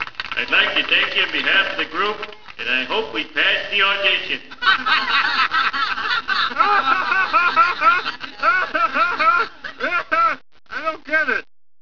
Homer quoting John Lennon's famous line from " Let it Be."